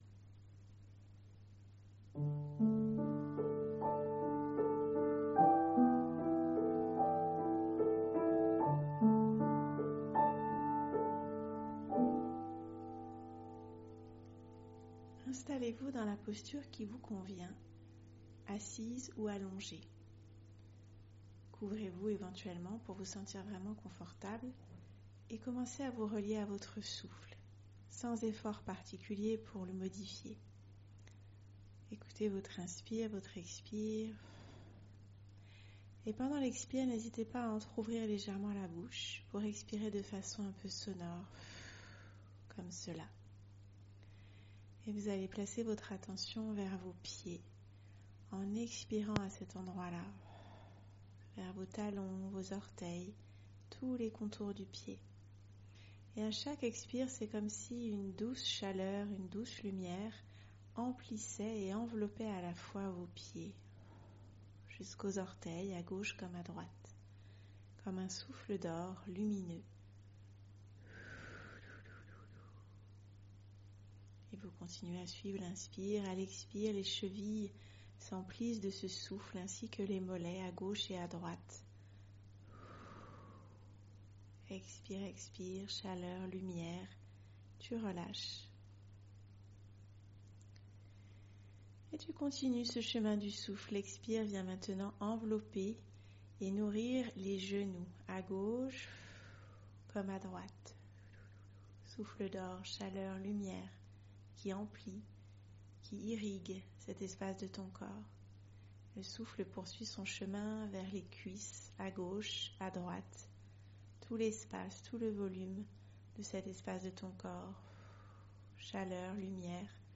compressedbijousonor1-scancorporel.mp3